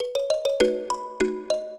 mbira
minuet8-10.wav